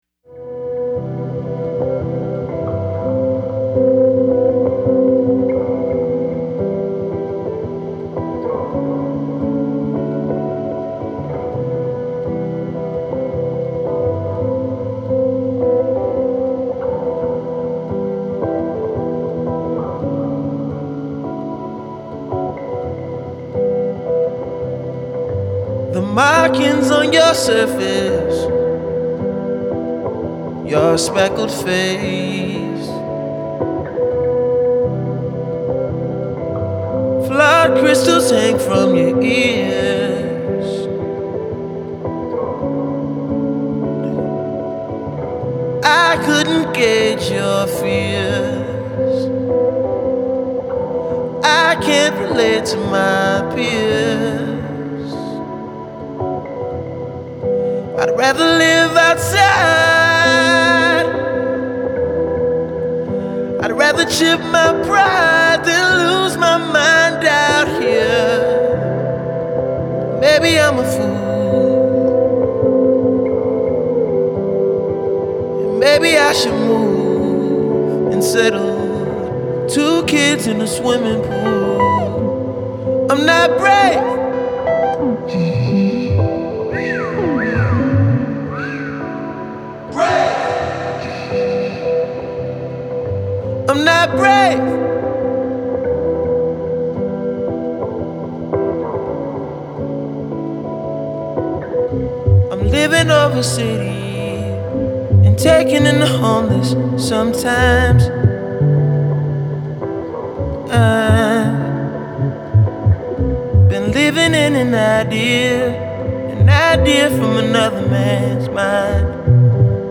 Жанр: R&B.